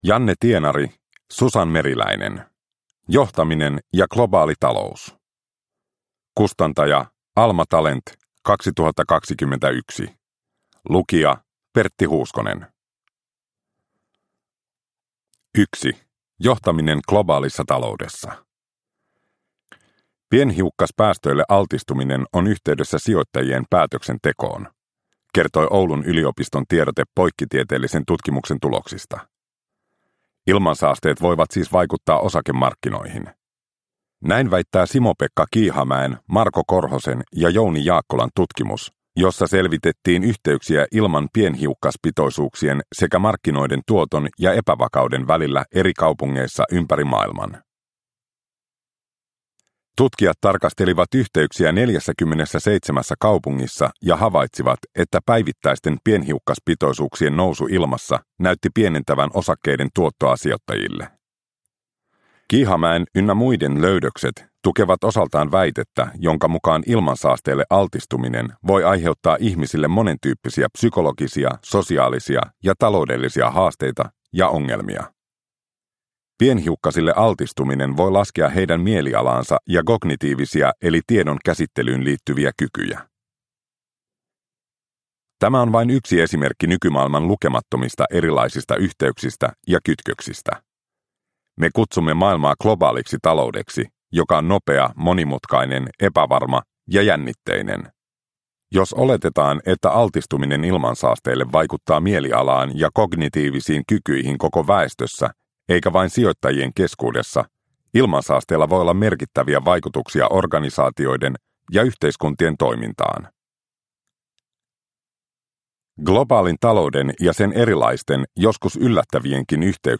Johtaminen ja globaali talous – Ljudbok – Laddas ner